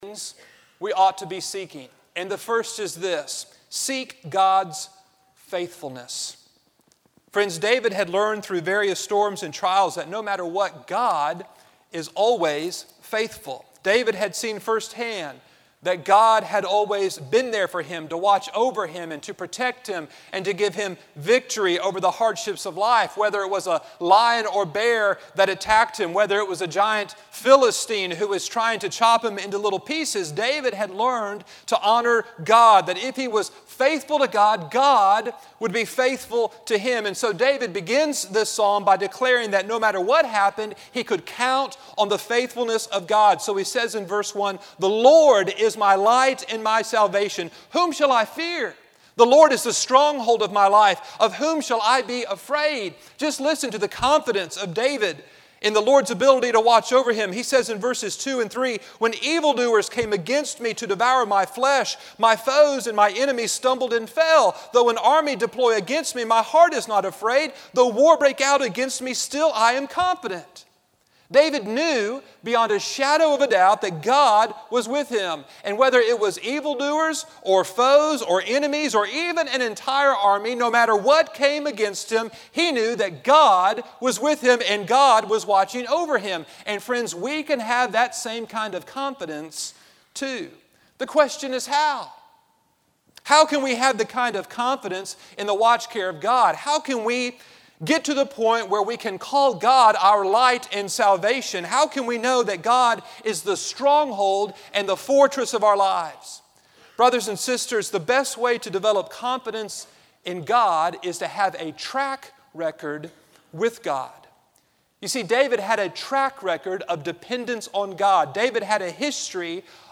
Address: My Heart's Cry - Psalm 27:1-14 Recording Date: Mar 20, 2009, 10:00 a.m. Length: 25:07 Format(s): MP3 ; Listen Now Chapels Podcast Subscribe via XML